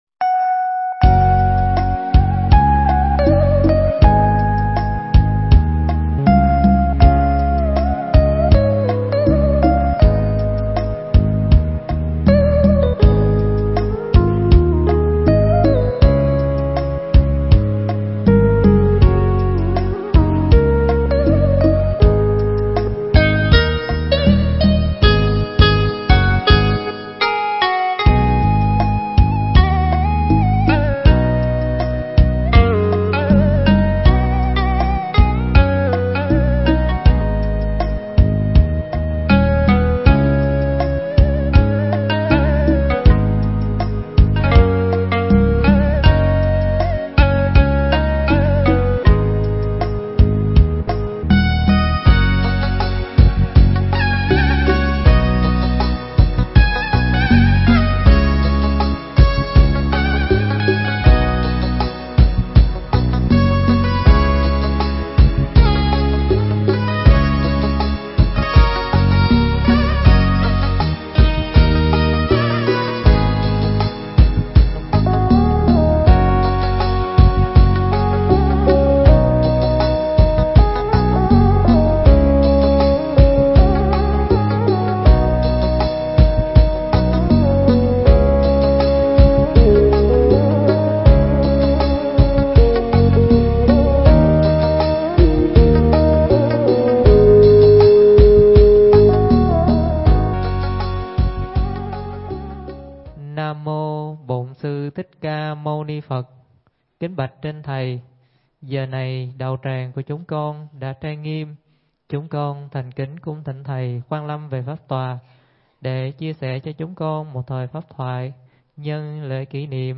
Mp3 Thuyết Giảng Việc Tốt Không Phải Dễ Làm
giảng tại Tu Viện Tường Vân